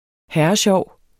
herresjov adjektiv Bøjning -t, -e Udtale [ ˈhæɐ̯ʌˈɕɒwˀ ] Betydninger meget sjov SPROGBRUG især talesprog uformelt Synonym smaddersjov Det er sgu da fedt og herresjovt!